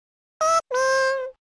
A Pikmin saying "Pikmin!" in the Nintendo logo screen of Pikmin.